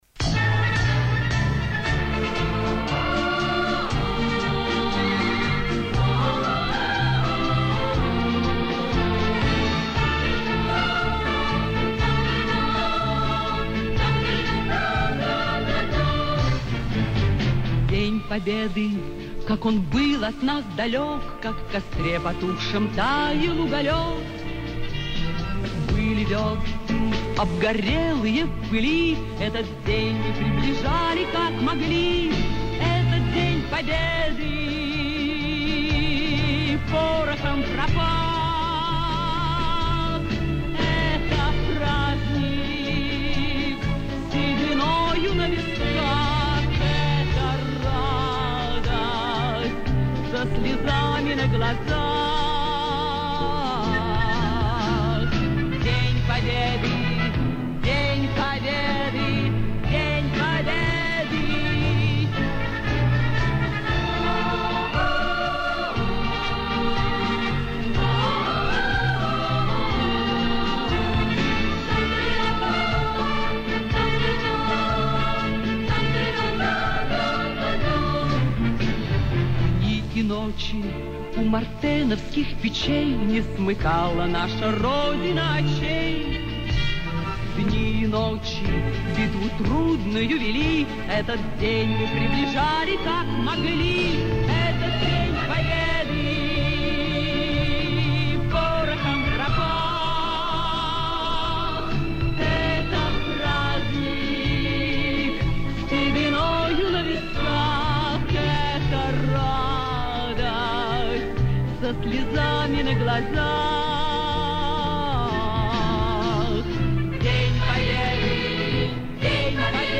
детский хор